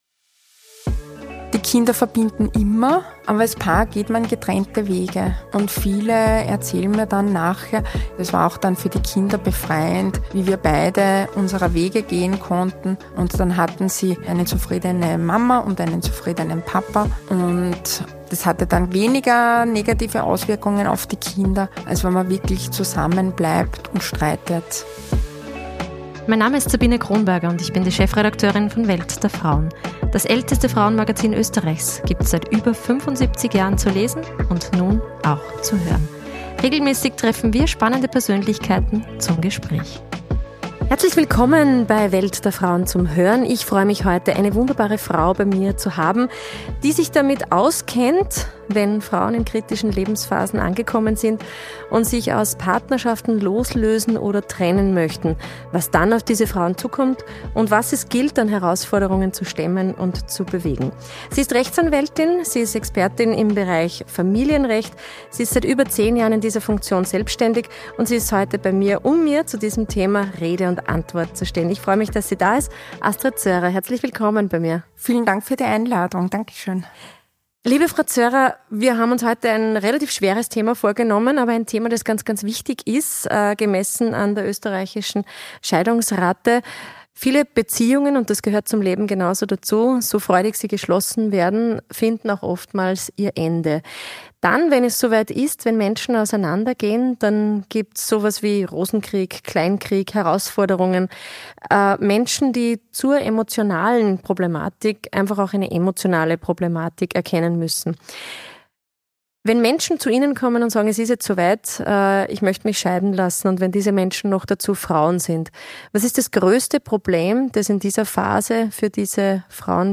Ein Gespräch über Trennung, Scheidung, streitige Verfahren, Schuldzuweisungen und das Bestreben, Kindern immer die beste Lösung anbieten zu wollen.